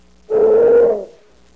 Bear Roaring Sound Effect
Download a high-quality bear roaring sound effect.
bear-roaring.mp3